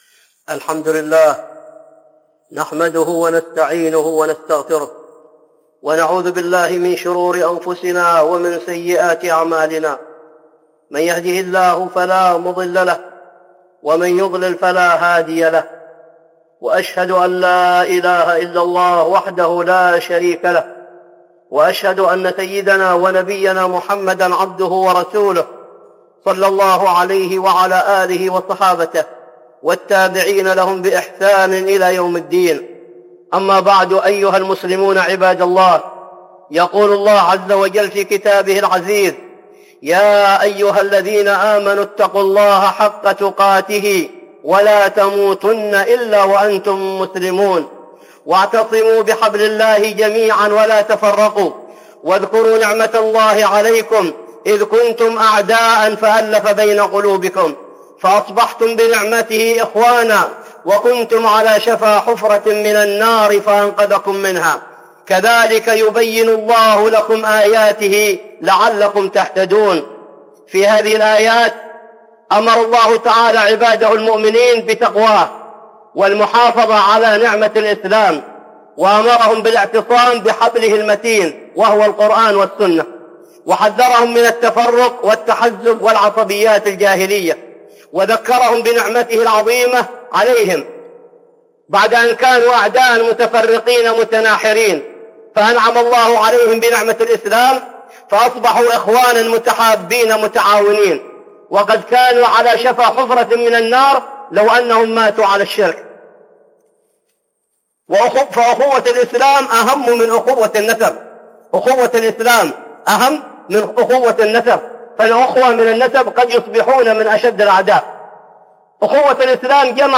(خطبة جمعة) الأخوة الإسلامية